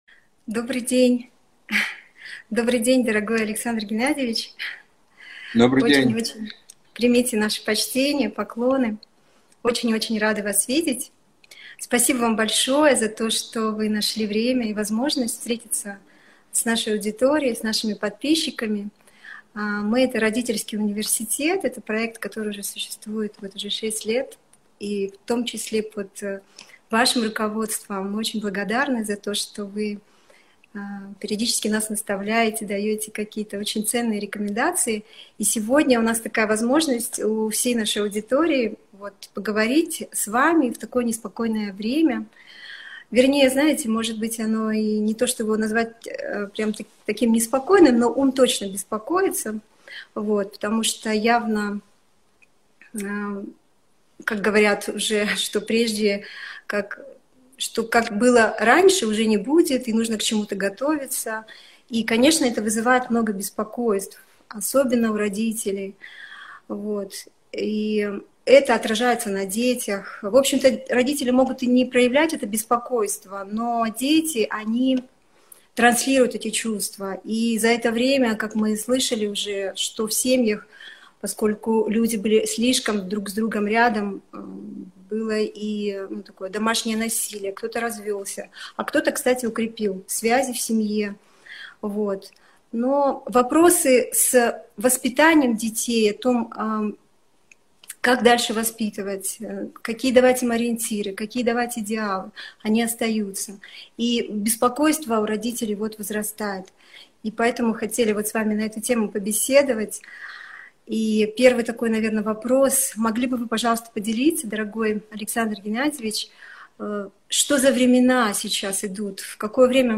Алматы, Беседа